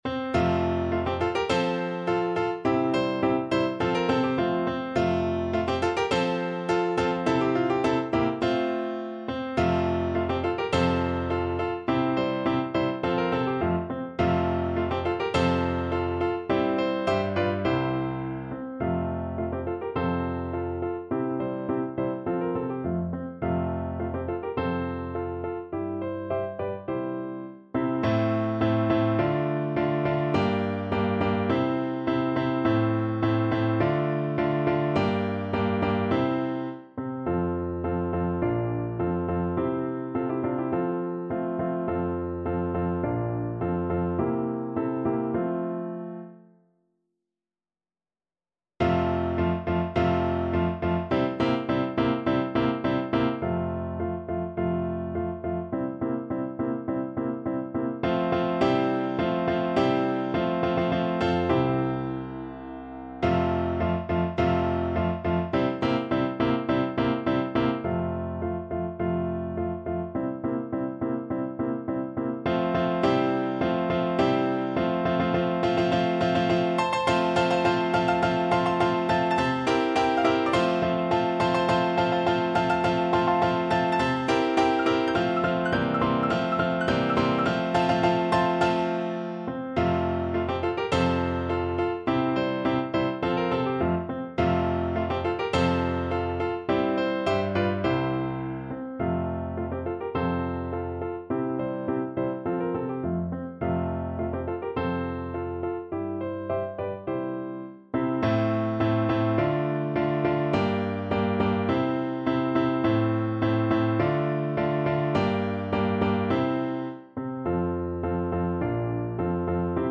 2/4 (View more 2/4 Music)
F major (Sounding Pitch) (View more F major Music for Flute )
~ = 100 Molto vivace =104
Classical (View more Classical Flute Music)